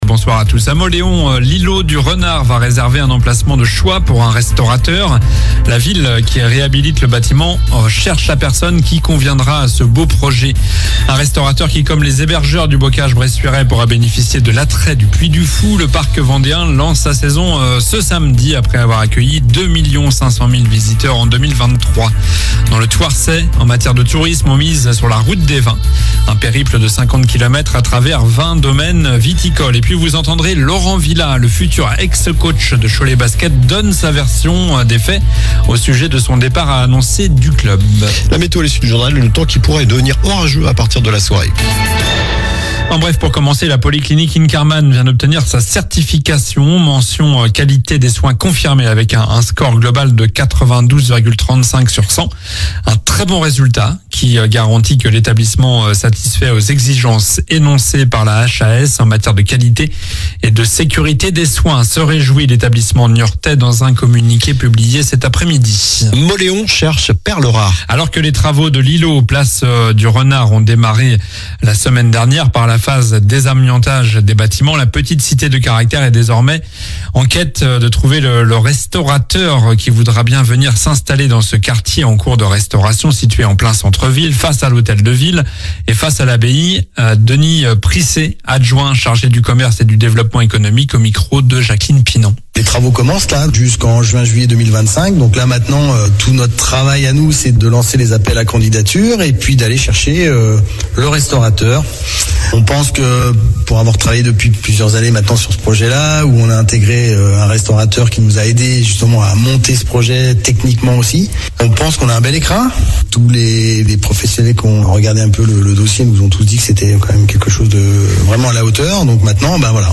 Journal du mercredi 27 mars (soir)